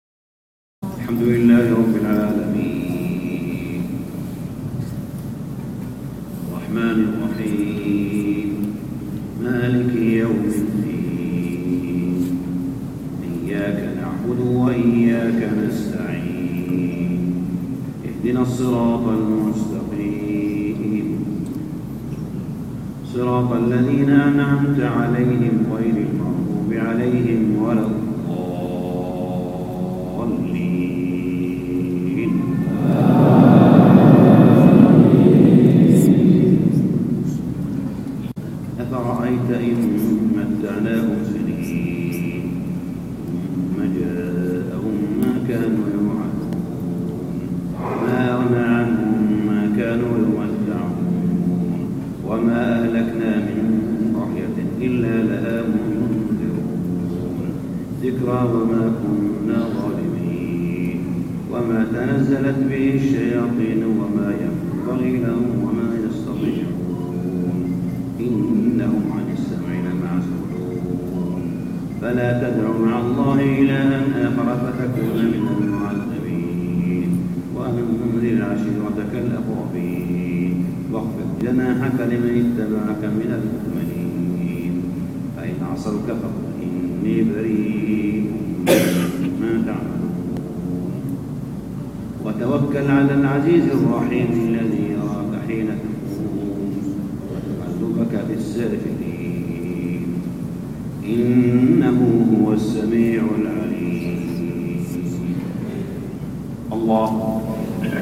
صلاة العشاء سورة الشعراء 205-220 ( الكويت 2013 ) | Isha prayer Surah Ash-Shu'ara > نوادر الشيخ صالح بن حميد > تلاوات و جهود الشيخ صالح بن حميد > المزيد - تلاوات الحرمين